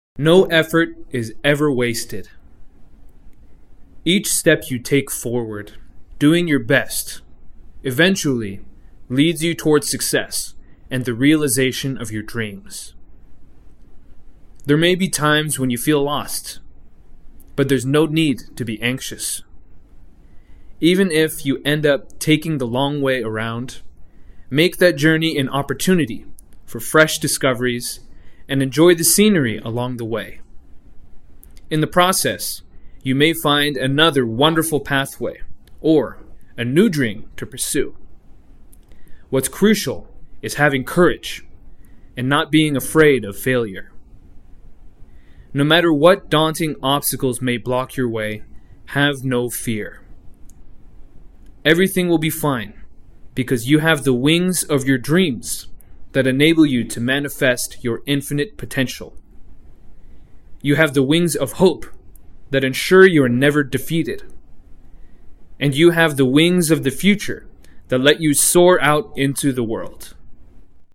模範音声再生（通常版） 模範音声再生（通常版）